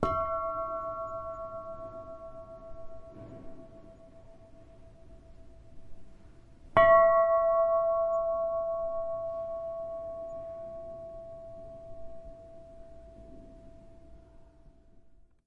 奥兰 " 船铃 6
描述：在ÅlandMarithamof Mariehamn海上博物馆用手（＃1至＃8）击中不同的船铃。
Tag: 叮当 现场记录